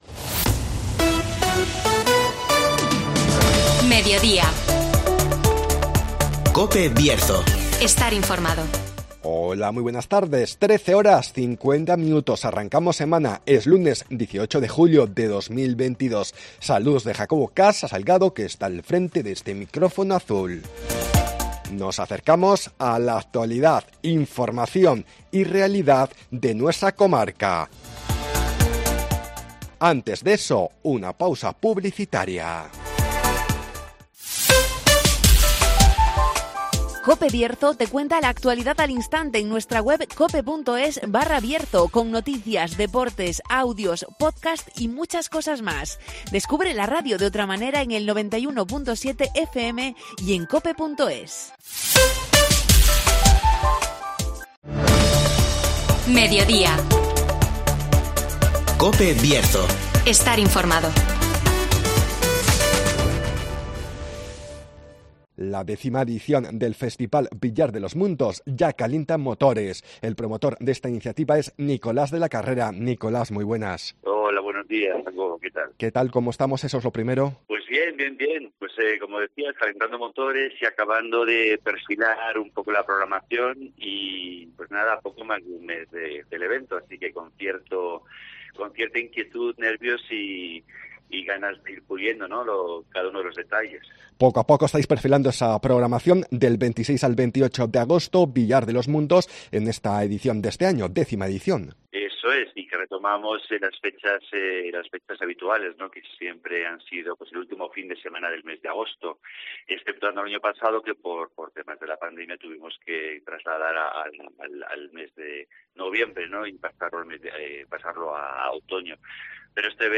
La décima edición del Festival Villar de los Mundos calienta motores (Entrevista